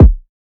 Kick [ bubble gum ].wav